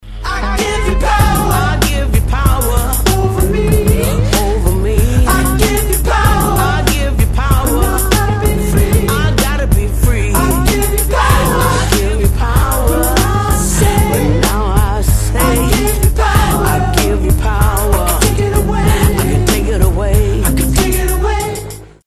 • Качество: 256, Stereo
indie rock
рок